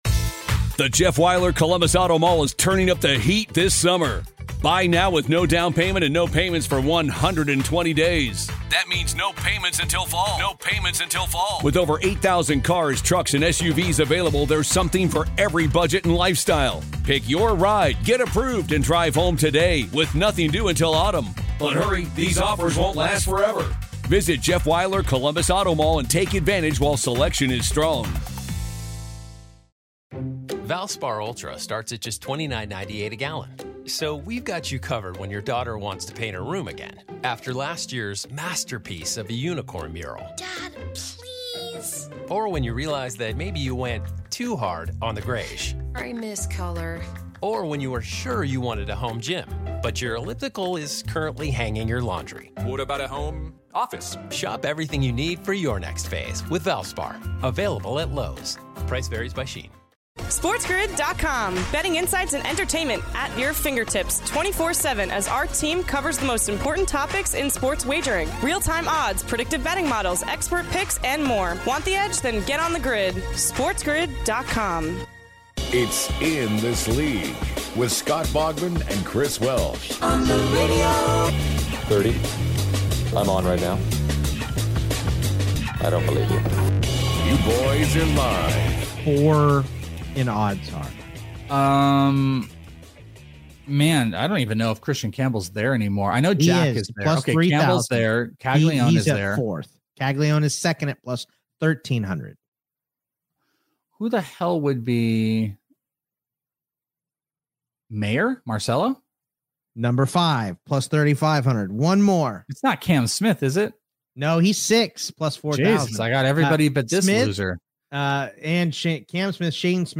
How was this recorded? go live on twitch to continue to break down week 13 of the MLB